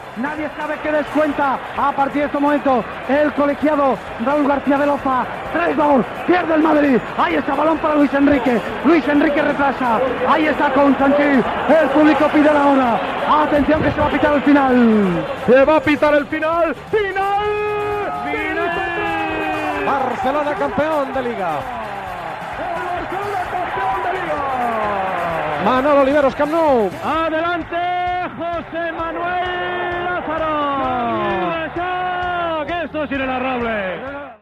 Transmissió de l'última jornada de la lliga masculina de futbol professional des dels camps del Tenerife i el Futbol Club Barcelona.
Final del partit a Tenerife i descripció de l'ambient al Camp Nou en guanyar el Futbol Club Barcelona la lliga en l'última jornada.